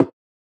edm-perc-45.wav